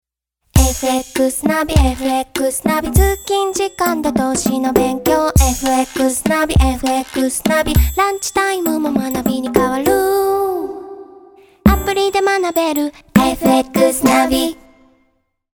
> Vocal
TVCM
SONG ELECTRO